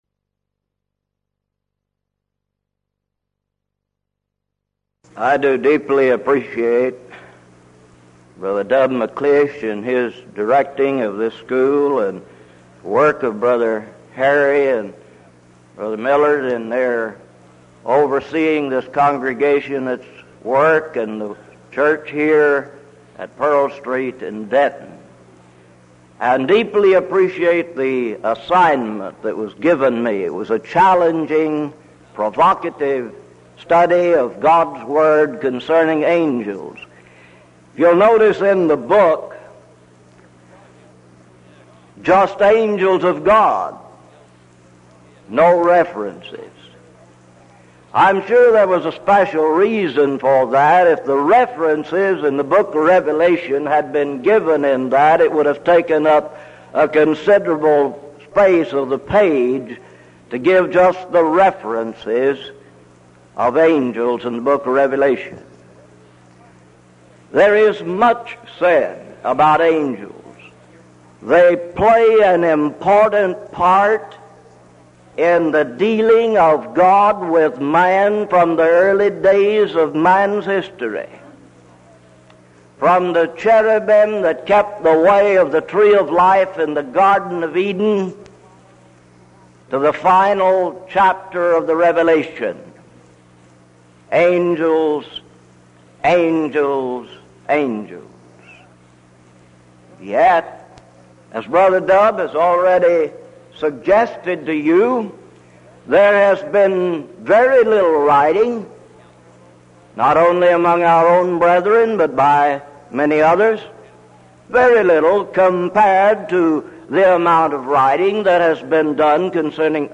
Event: 1984 Denton Lectures Theme/Title: Studies in the Book of Revelation
lecture